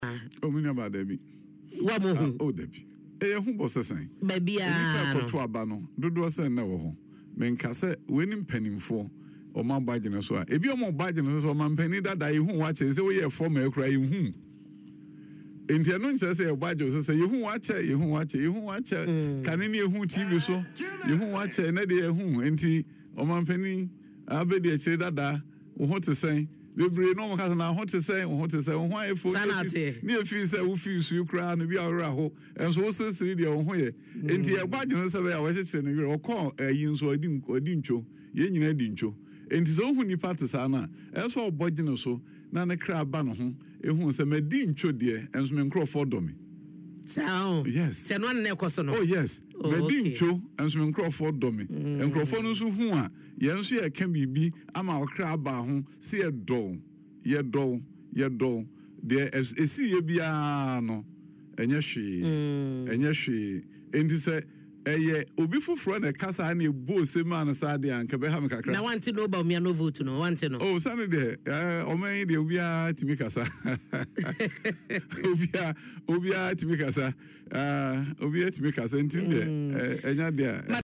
Speaking in an interview on Adom FM’s morning show Dwaso Nsem, Dr. Adutwum said he had no reason to be worried or scared.